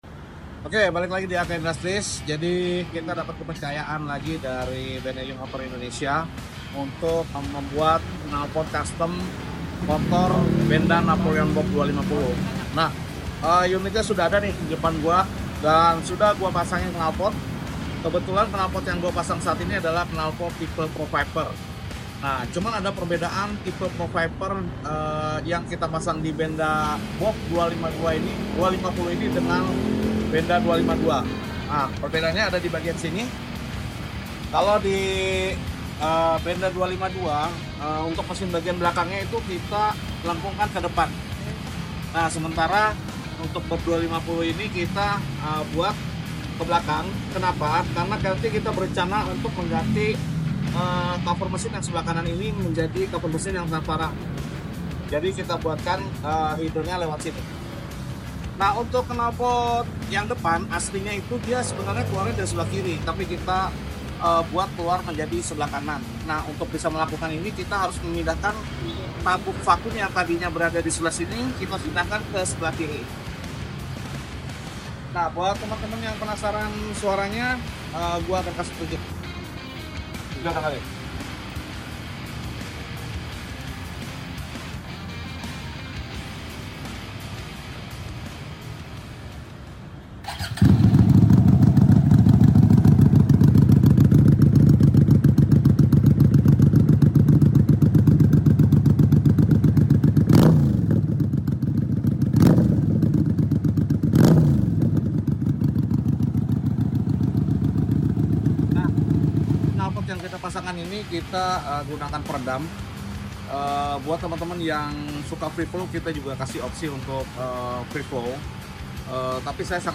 REVIEW KNALPOT PRO VIPER DI sound effects free download